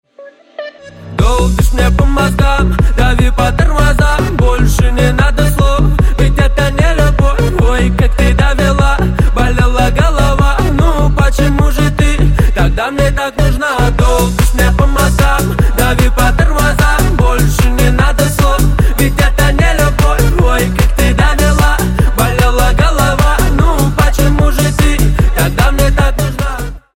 Весёлые Рингтоны
Поп Рингтоны